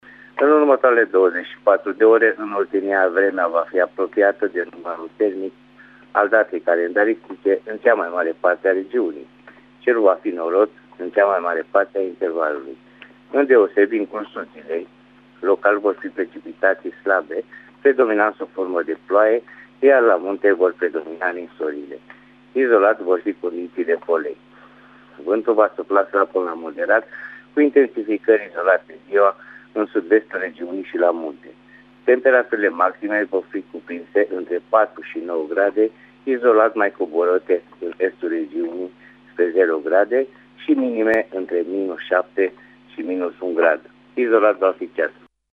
Prognoza meteo 5 decembrie (audio)